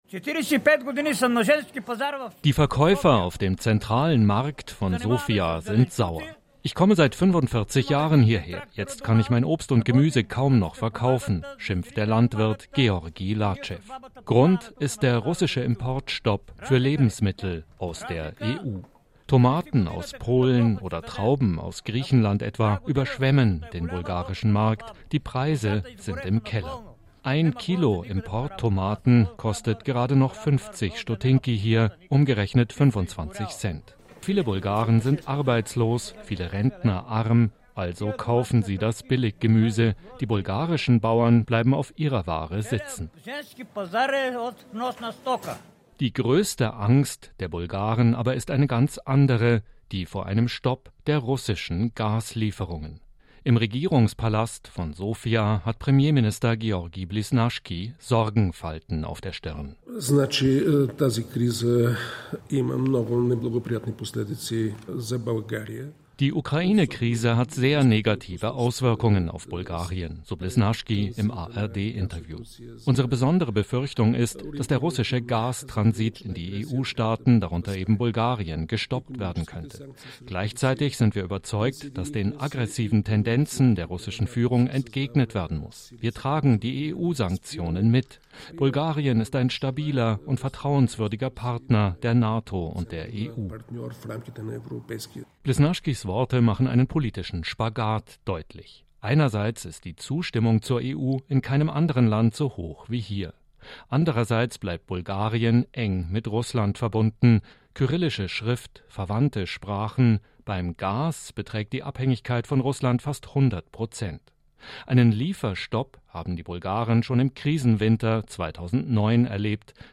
Premierminister Georgi Blisnaschki spricht im ARD-Interview über die Angst vor einem russischen Gas-Lieferstopp. Bulgarien ist zu fast 100 Prozent von russischem Gas abhängig.